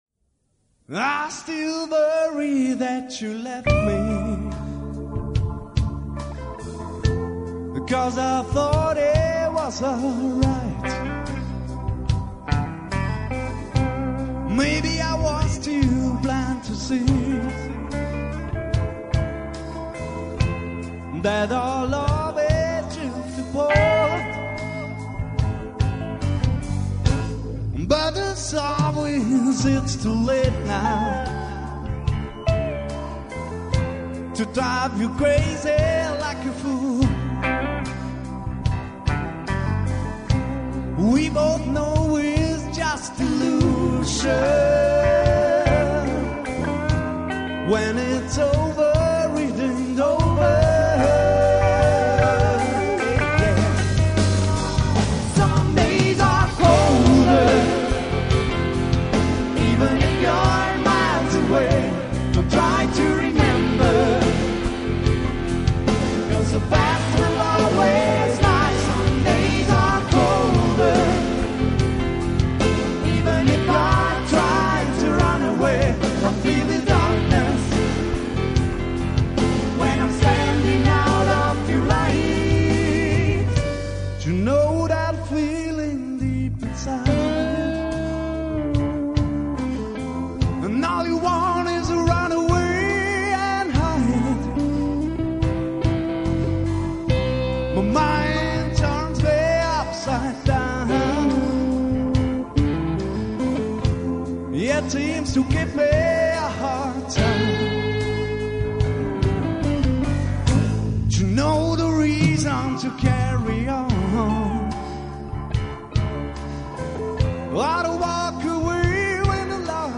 Live-Mitschnitt Open-Air
Lead Vocals & Bass